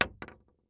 Bullet Shell Sounds
shotgun_wood_8.ogg